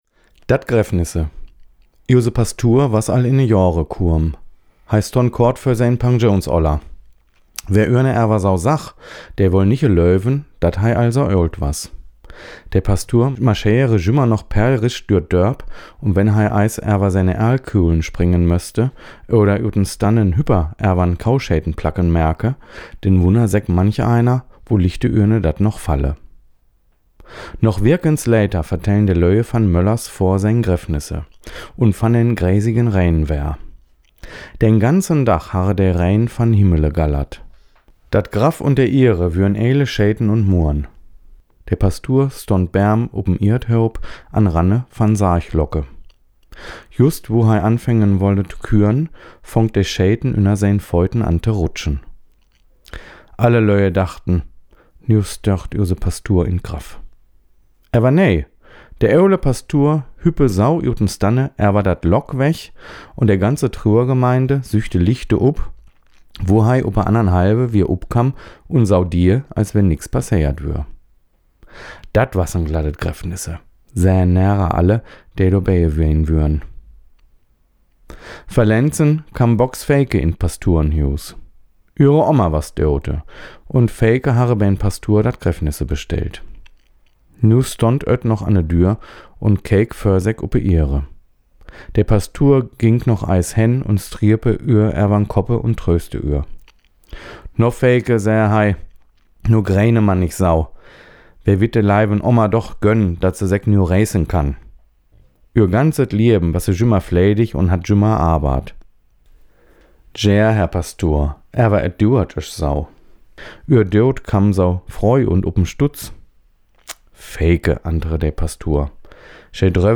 Horster Platt
Dat-Begräffnis-Horster-Platt.mp3